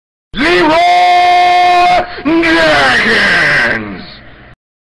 Leeroy Jenkins - Bouton d'effet sonore